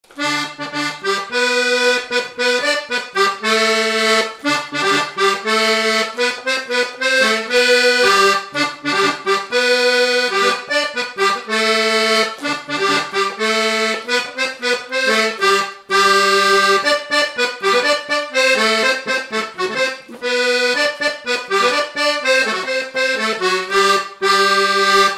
Vendée
Usage d'après l'analyste gestuel : danse
Catégorie Pièce musicale inédite